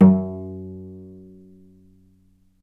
healing-soundscapes/Sound Banks/HSS_OP_Pack/Strings/cello/pizz/vc_pz-F#2-ff.AIF at ae2f2fe41e2fc4dd57af0702df0fa403f34382e7
vc_pz-F#2-ff.AIF